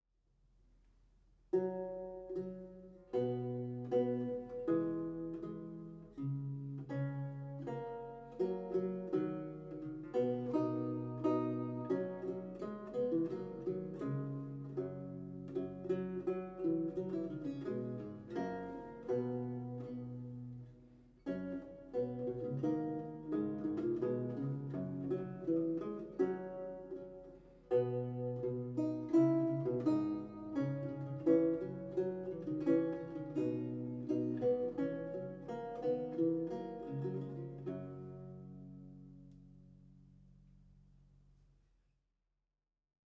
a 16th century lute music piece
Audio recording of a lute piece